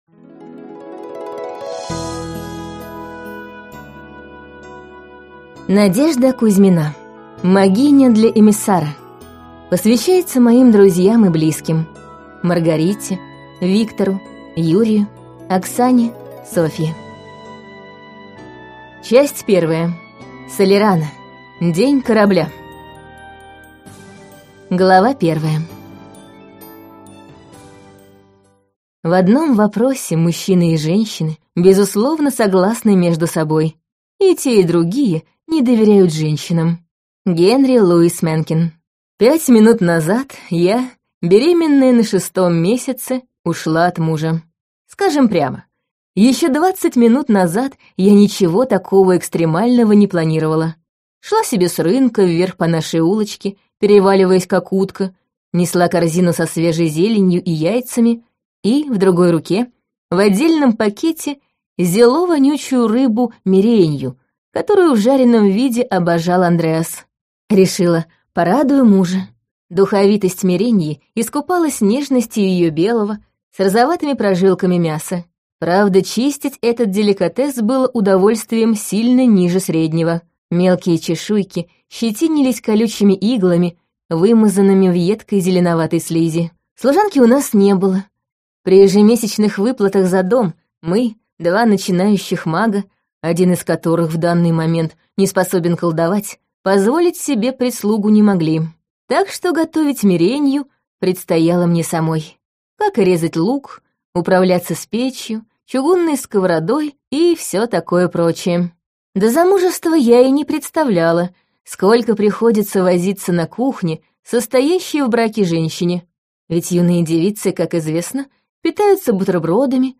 Аудиокнига Магиня для эмиссара. Часть первая. Салерано. День корабля | Библиотека аудиокниг